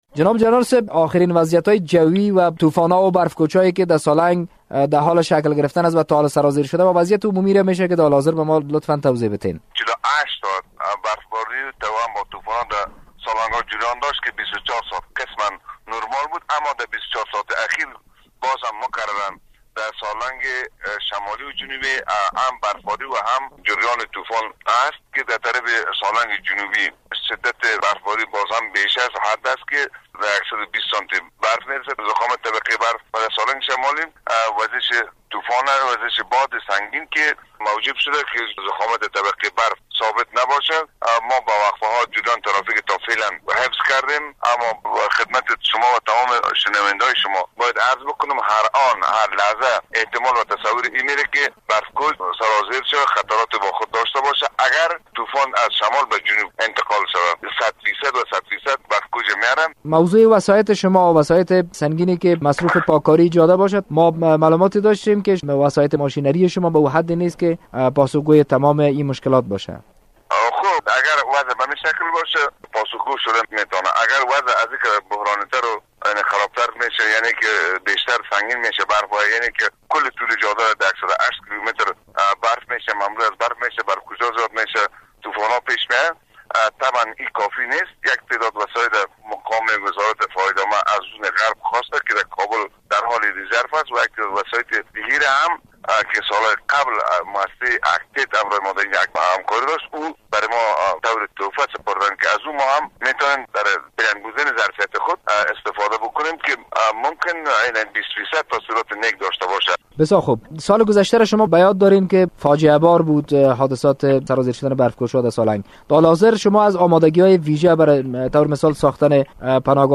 مصاحبه با جنرال رجب رییس ادارهء حفظ و مراقبت شاهراه سالنگ در مورد تدابیر اتخاد شده در زمستان امسال